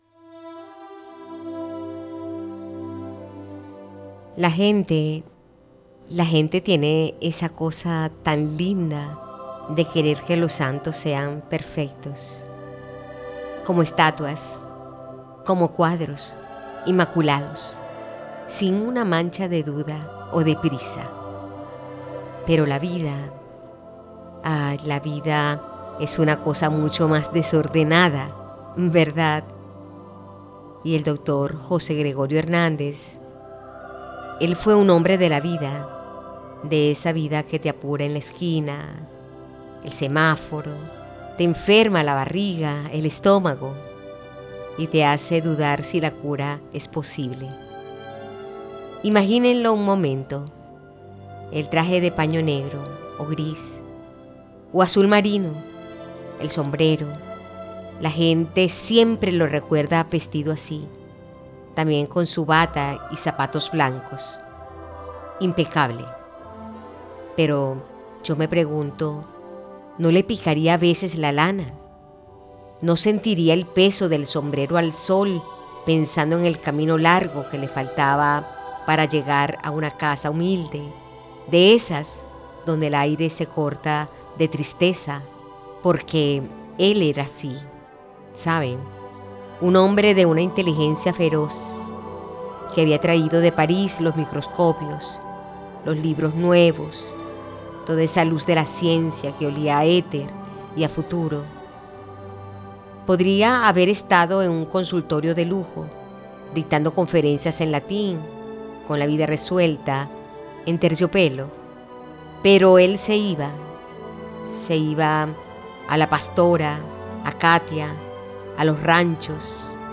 Aula de Letras al Aire es un programa de radio universitaria que lleva la literatura (poemas y relatos) más allá del aula, ofreciendo una experiencia auditiva y accesible para la comunidad universitaria y el público en general.